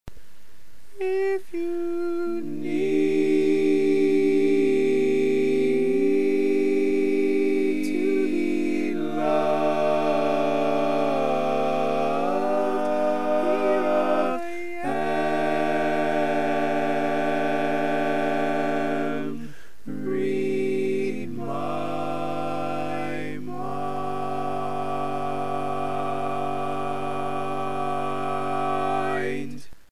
Key written in: A Major
How many parts: 4
Type: Barbershop
Learning tracks sung by